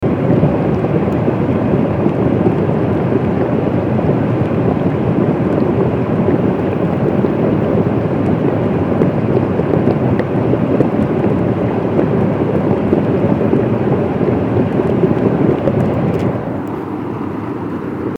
Loud Water Rushing Sound Effect Download: Instant Soundboard Button